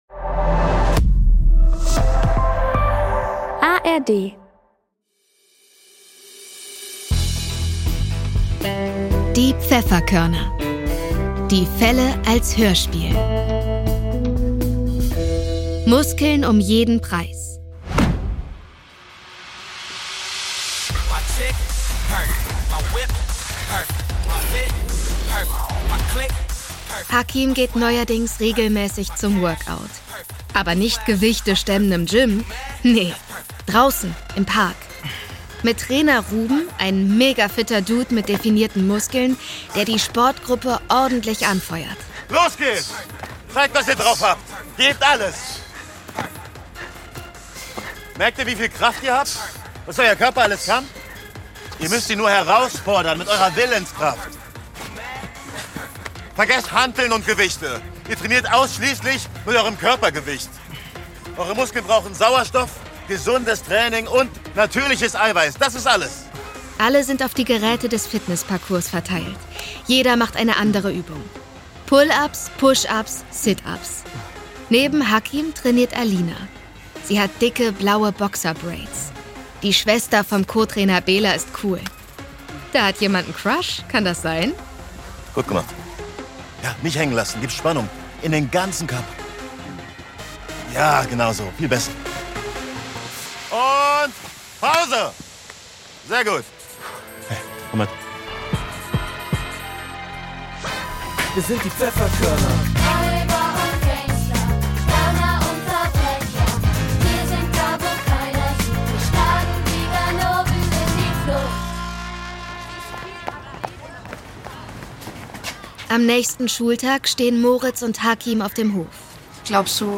Muskeln um jeden Preis (21/26) ~ Die Pfefferkörner - Die Fälle als Hörspiel Podcast